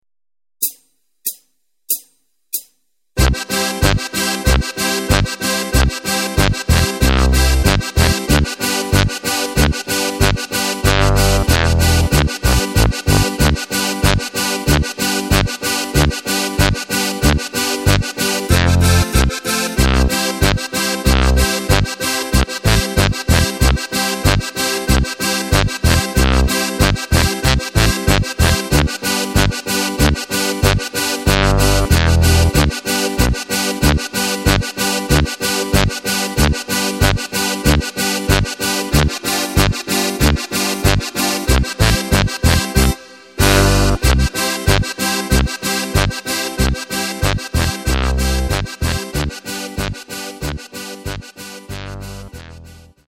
Takt:          2/4
Tempo:         94.00
Tonart:            C
Playback mp3
Playback Demo